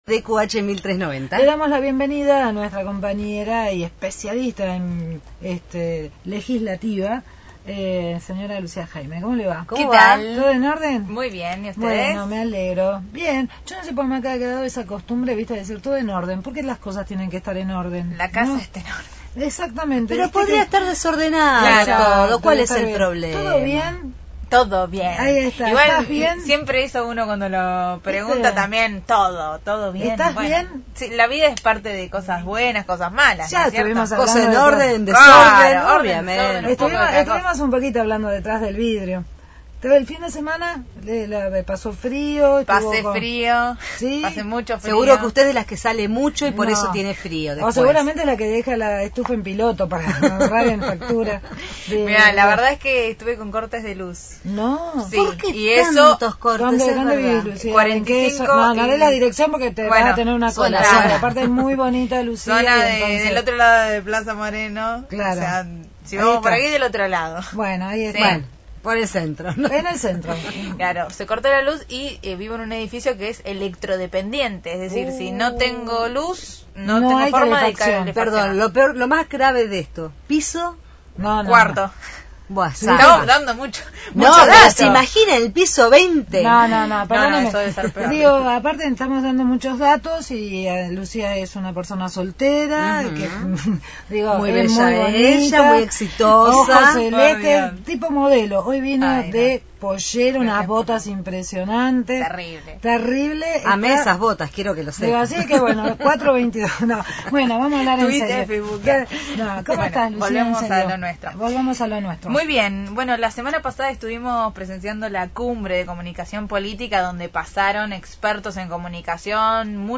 Entrevista al Senador por Cambiemos Roberto Costa